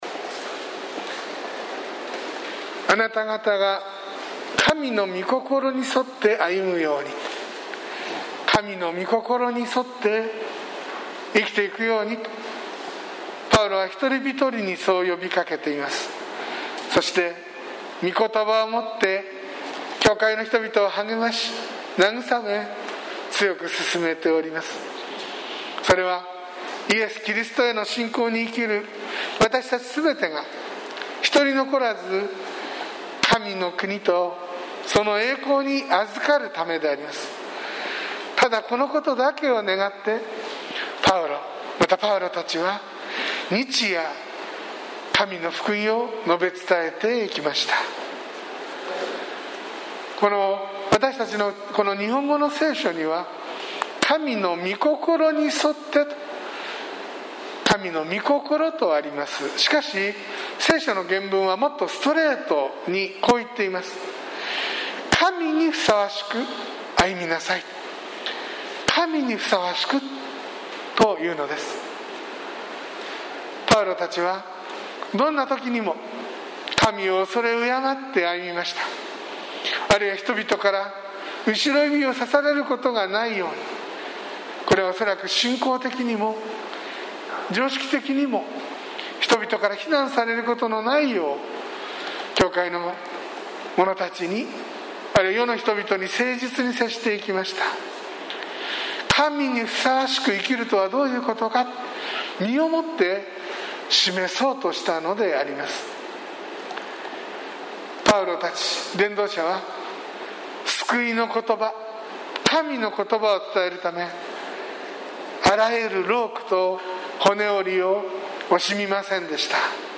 sermon2020-06-21